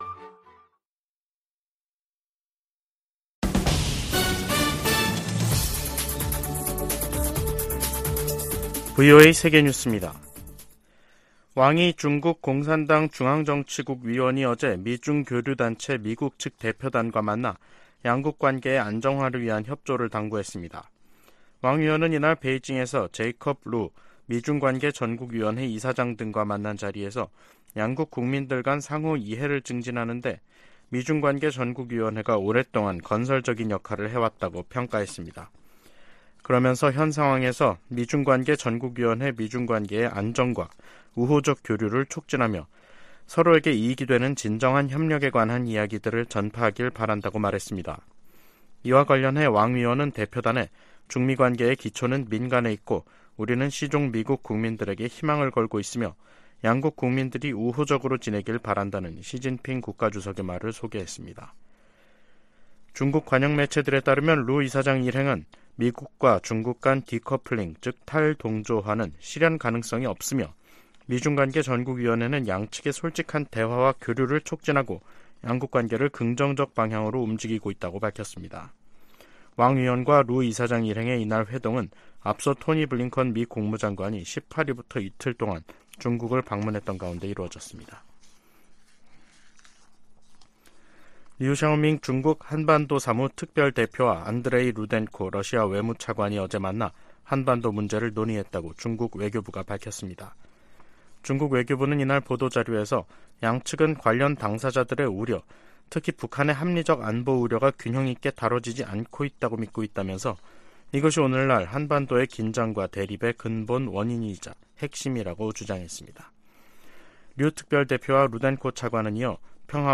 VOA 한국어 간판 뉴스 프로그램 '뉴스 투데이', 2023년 6월 27일 2부 방송입니다. 한반도 긴장 고조 책임이 미국에 있다고 북한이 비난한데 대해 미 국무부는 역내 긴장을 고조시키는 건 북한의 도발이라고 반박했습니다. 미 국무부는 러시아 용병기업 바그너 그룹의 무장 반란 사태가 바그너와 북한 간 관계에 미칠 영향을 판단하기는 이르다고 밝혔습니다. 백악관 인도태평양조정관은 한국 등 동맹과의 긴밀한 관계가 인도태평양 전체의 이익에 부합한다고 말했습니다.